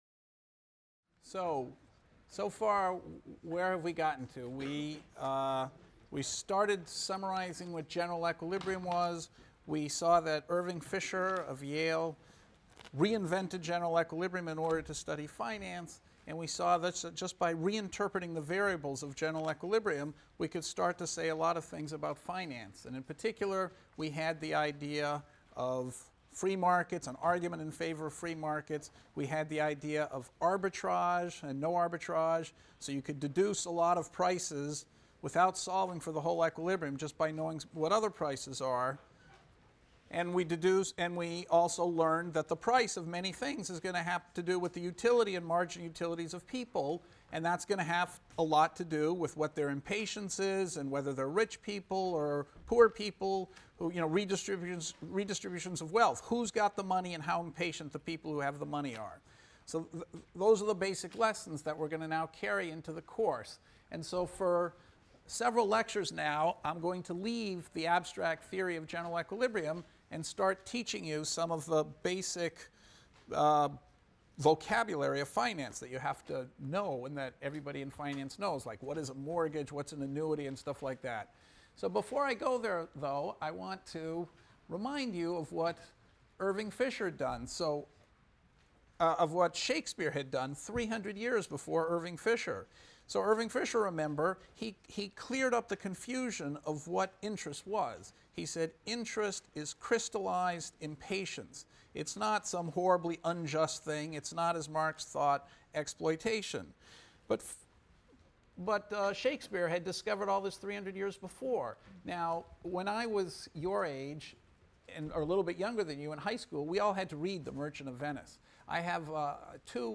ECON 251 - Lecture 7 - Shakespeare’s Merchant of Venice and Collateral, Present Value and the Vocabulary of Finance | Open Yale Courses